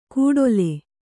♪ kūḍole